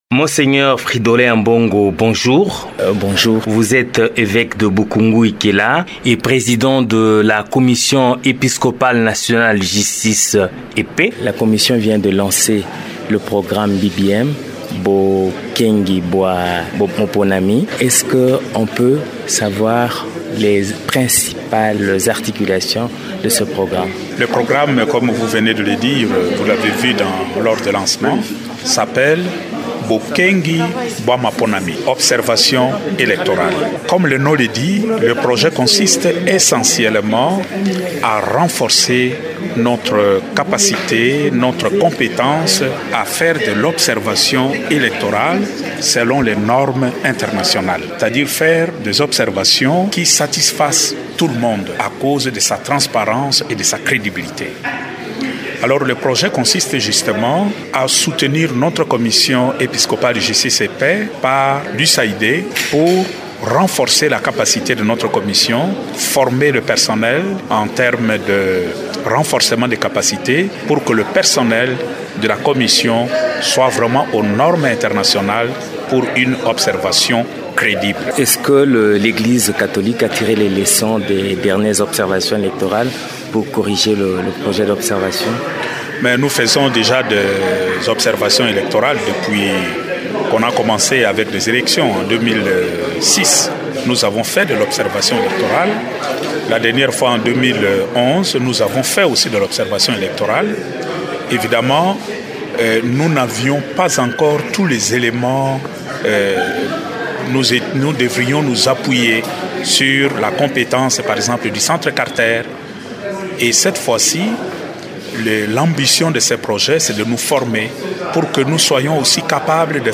Le président de la commission épiscopale nationale justice et paix, Mgr Fridolin Ambongo, parle de ce projet.